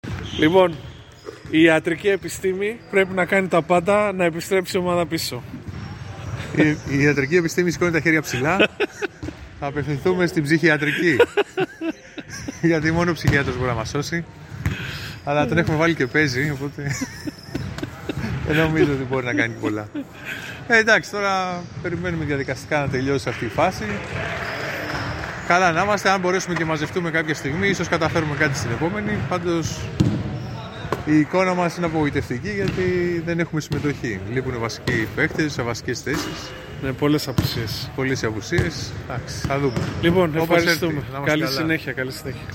GAME INTERVIEWS